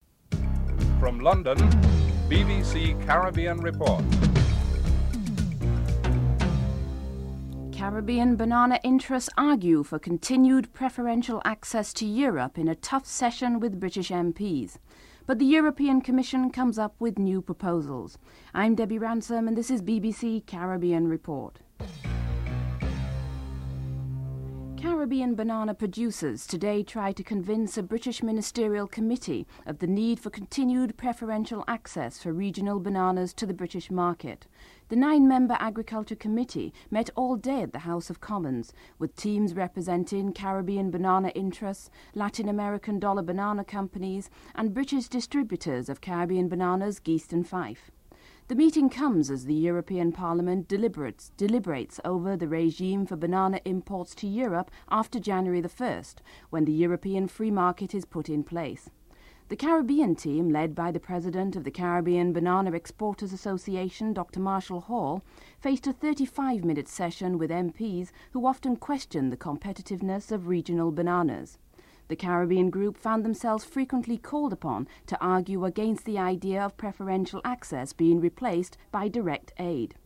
The British Broadcasting Corporation
1. Headlines (00:00-00:23)